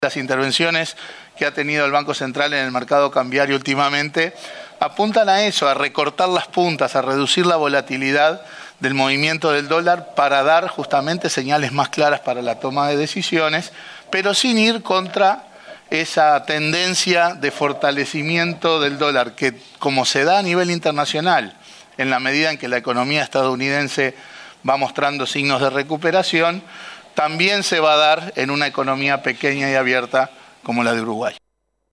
Bergara dijo en rueda de prensa que las intervenciones en el mercado son para moderar la cotización.